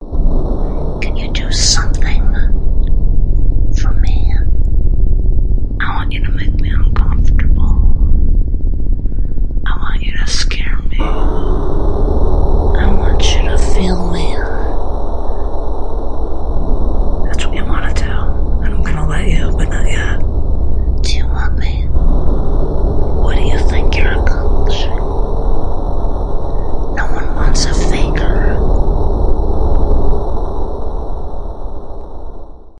女人性感嘘声
描述：女人耳语shhhh 4次分开。慢慢嘘。
标签： 性感 窃窃私语 顽皮 蒸汽 女人 咖啡
声道立体声